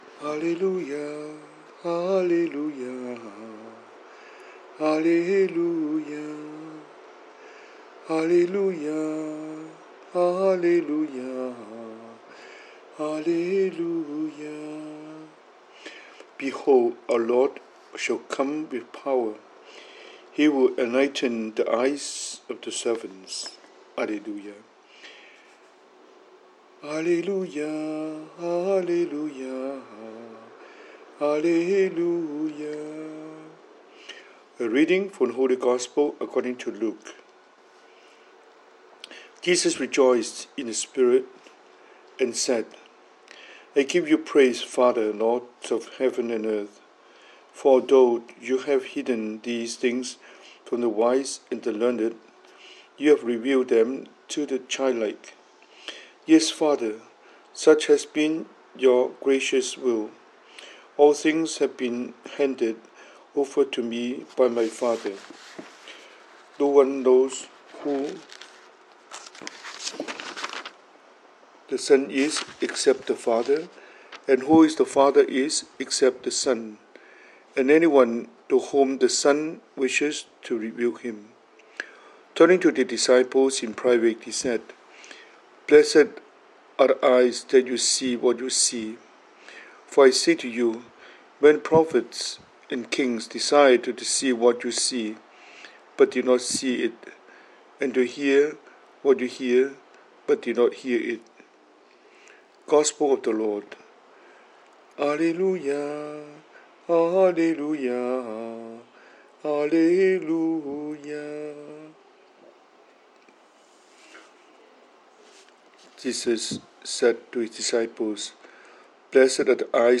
英文講道